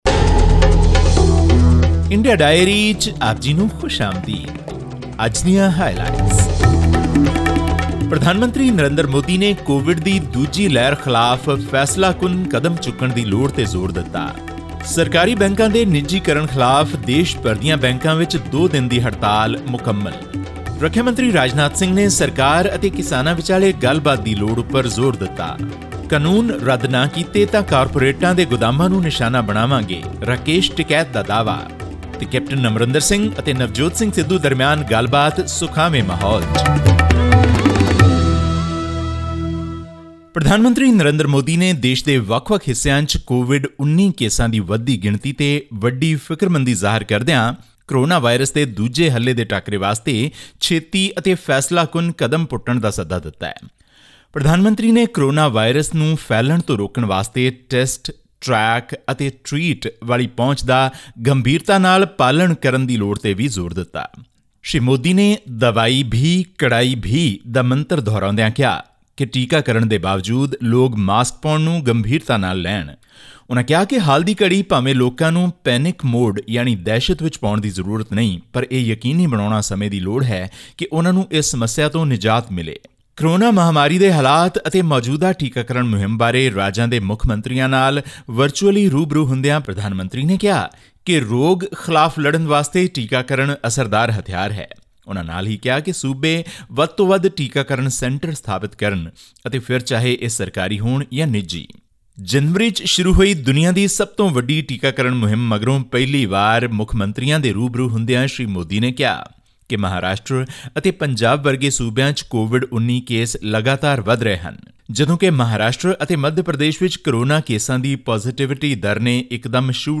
Prime Minister Narendra Modi has expressed his concern over the escalating coronavirus situation in some Indian states and emphasised that the emerging second wave of COVID-19 cases must be contained immediately. This and more in our weekly news segment from India.